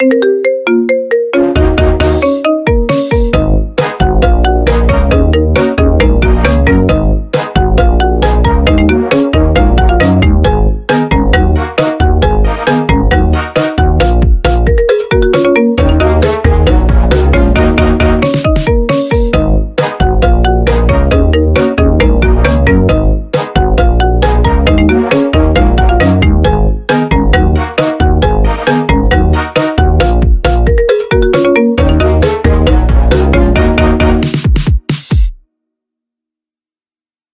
Marimba Ringtone